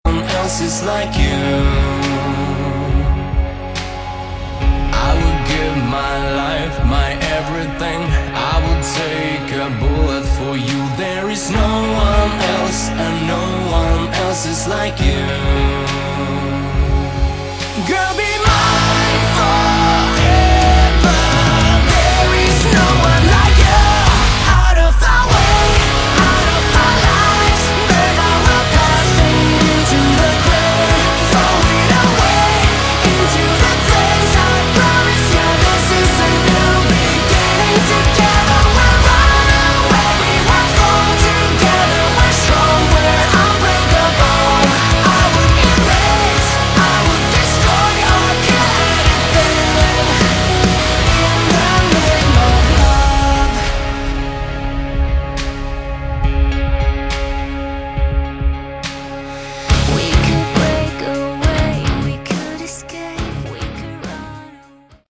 Category: Melodic Metal
vocals
guitars
keyboards
bass
drums